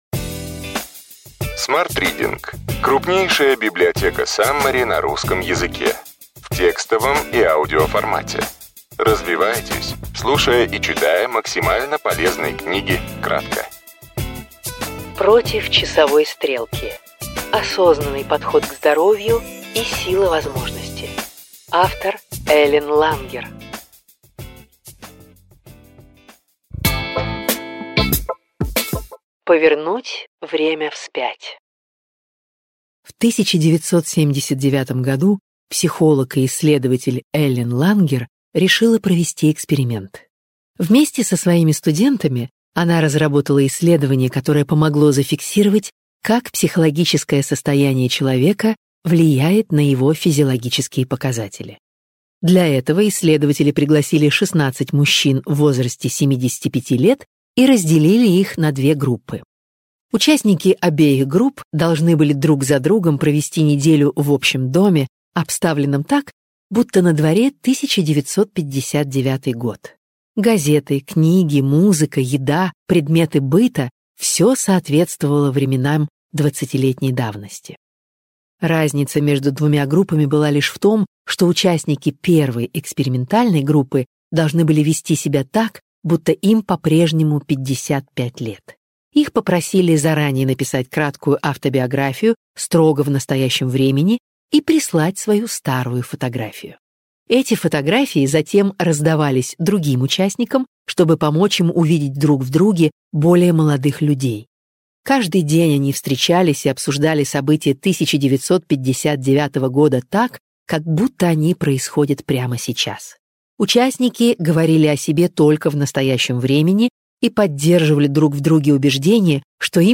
Аудиокнига Ключевые идеи книги: Против часовой стрелки. Осознанный подход к здоровью и сила возможности. Эллен Лангер | Библиотека аудиокниг